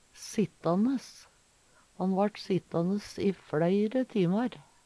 sittanes - Numedalsmål (en-US)